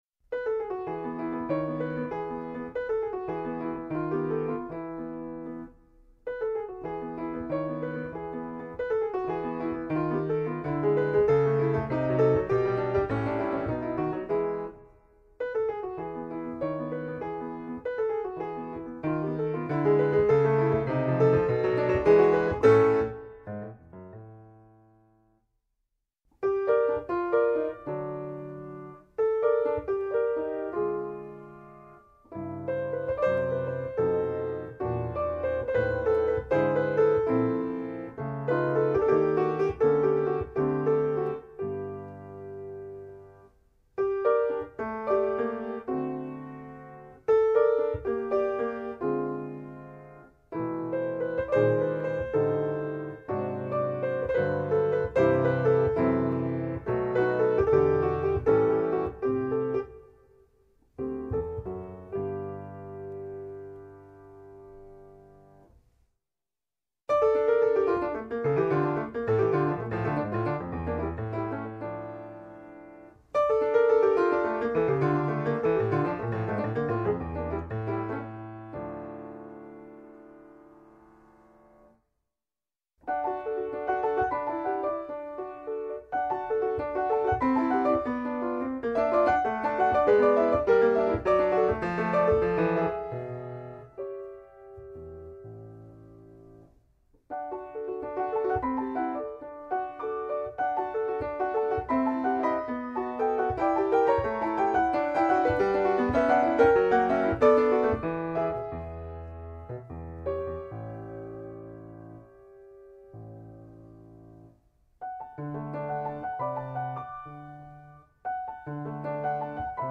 mezzo di esecuzione: pianoforte